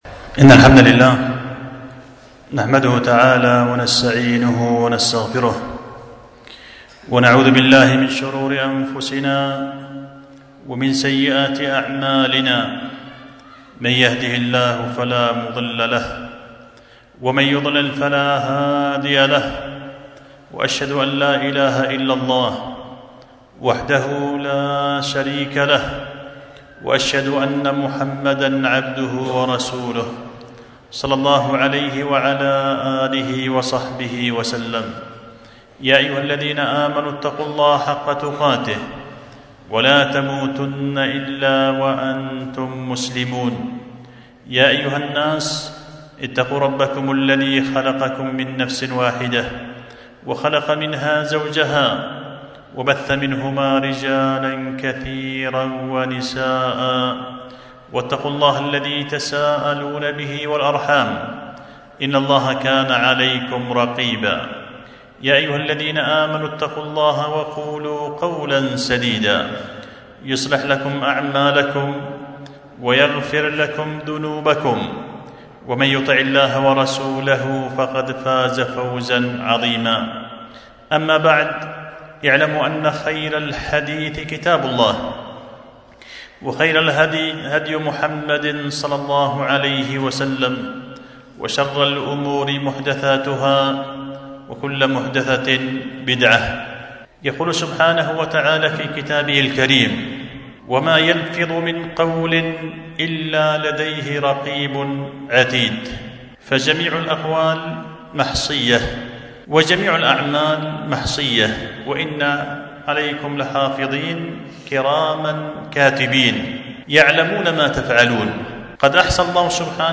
خطبة جمعة بعنوان منثور الكلام والجمل في الحث على استقبال رمضان بحسن العمل